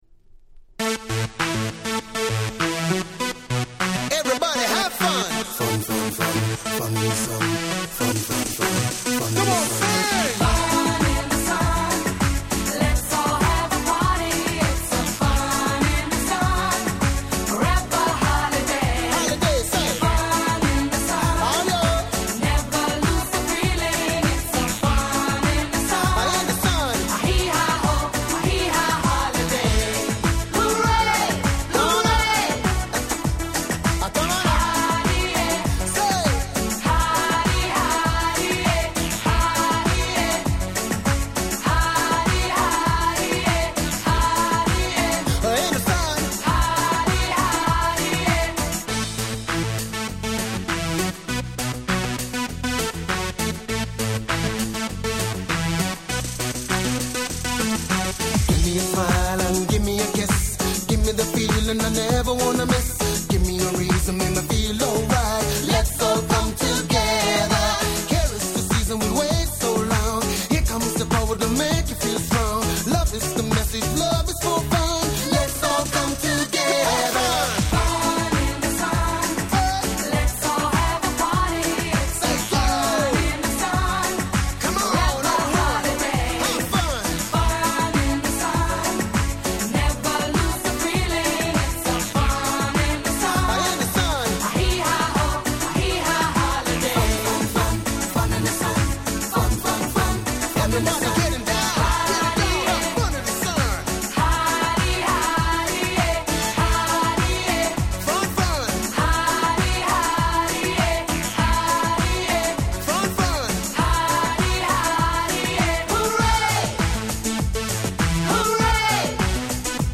97' Nice Dance Pop !!
夏向きのキャッチーParty Tuneです！！